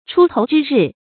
出头之日 chū tóu zhī rì
出头之日发音